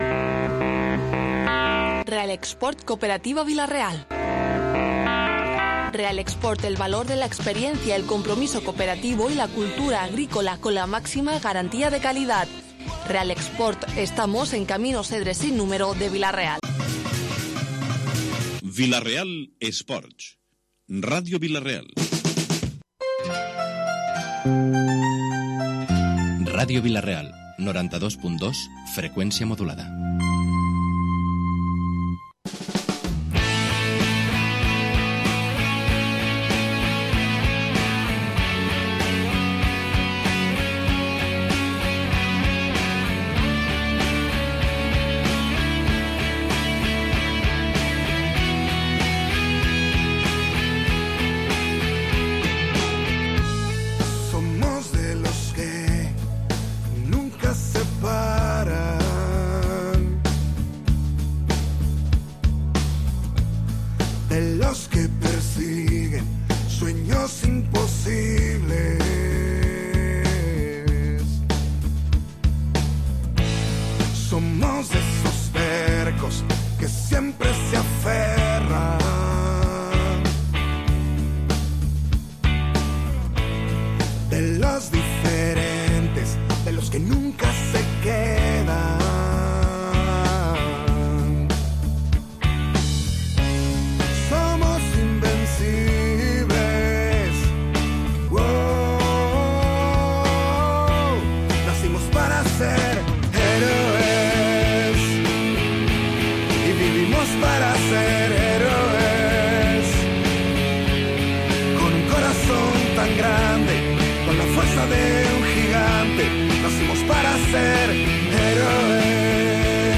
Tertulia Grogueta Radio Vila-real Dilluns 31 de Maig